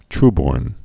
(trbôrn)